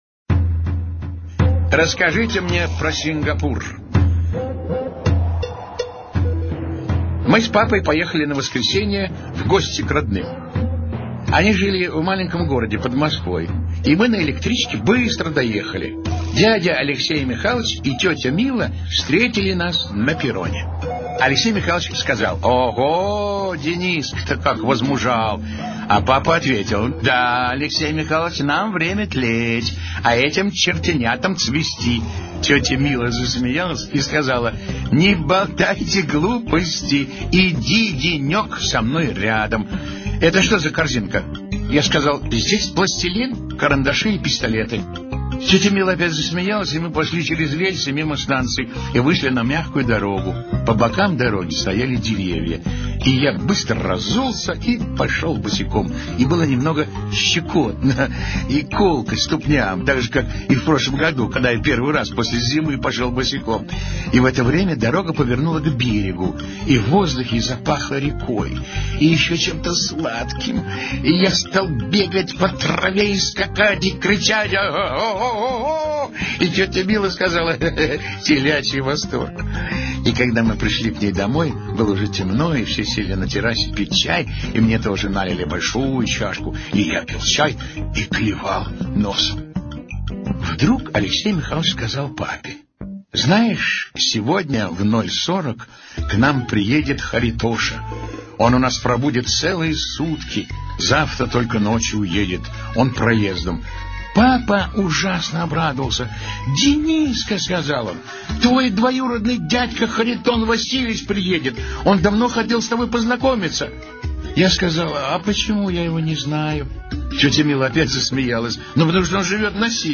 Слушайте Расскажите мне про Сингапур - аудио рассказ Драгунского В.Ю. Однажды Дениска с папой ночевали в гостях у родственников.